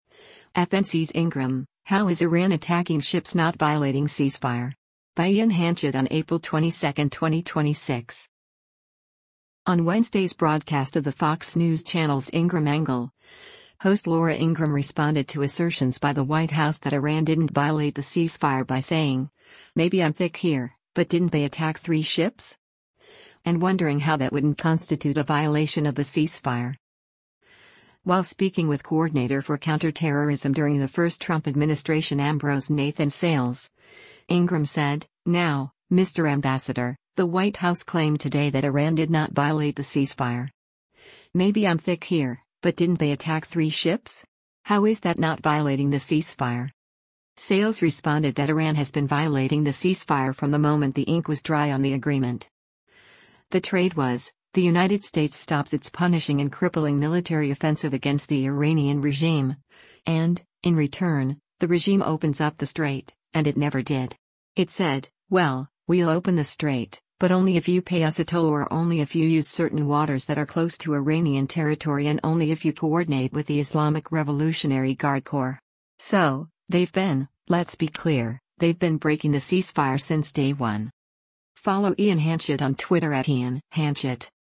On Wednesday’s broadcast of the Fox News Channel’s “Ingraham Angle,” host Laura Ingraham responded to assertions by the White House that Iran didn’t violate the ceasefire by saying, “Maybe I’m thick here, but didn’t they attack three ships?” And wondering how that wouldn’t constitute a violation of the ceasefire.